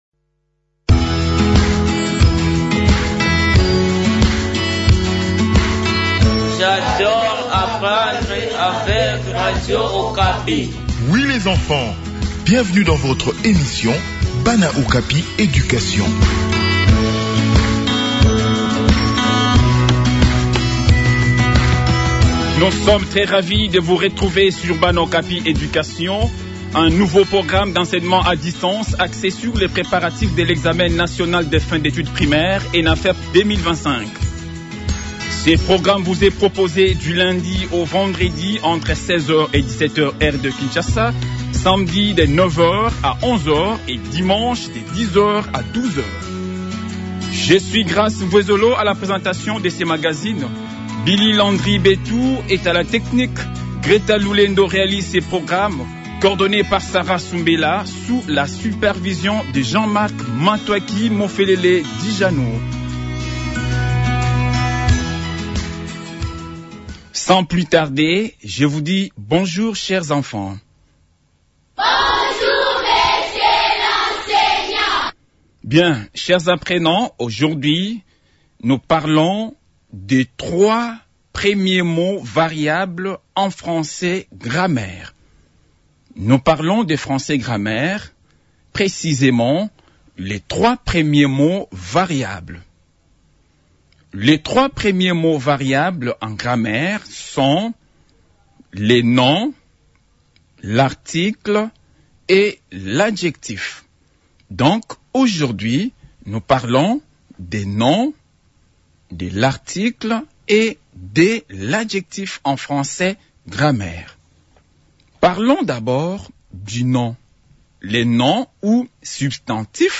Préparation des examens nationaux : Leçon de Grammaire